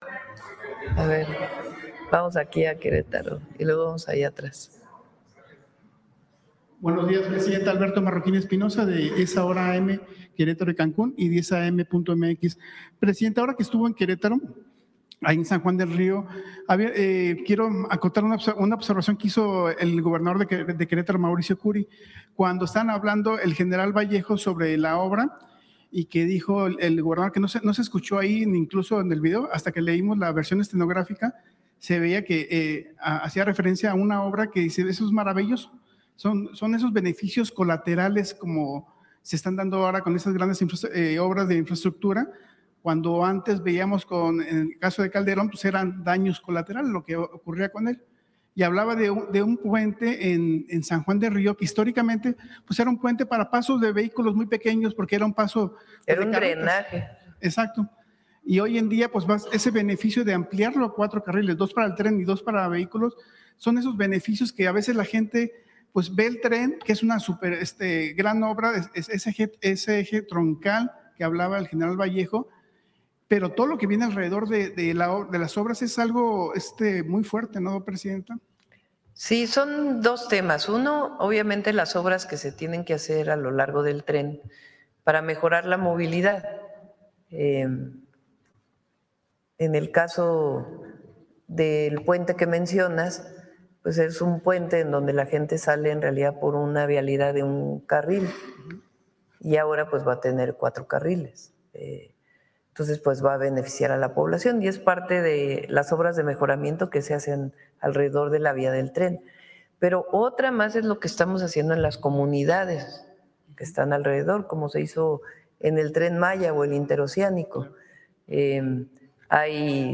Radio am pregunte a la presidenta
mananera.mp3